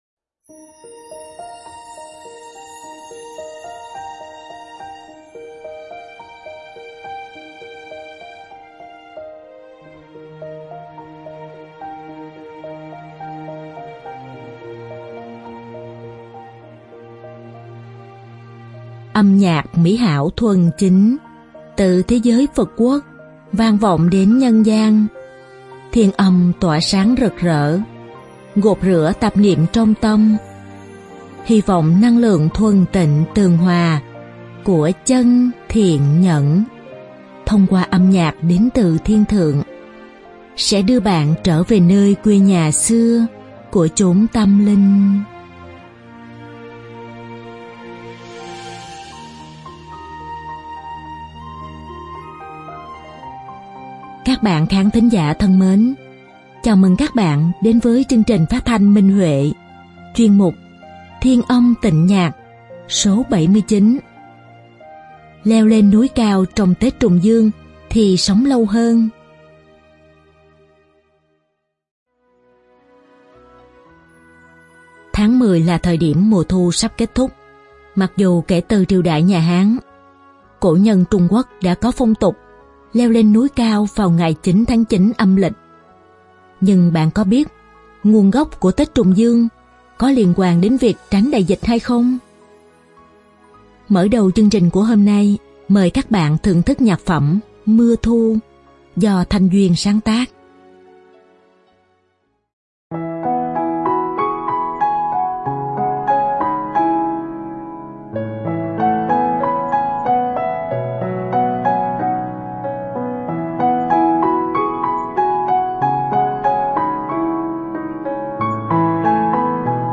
Nữ hợp xướng
Nam nữ hợp xướng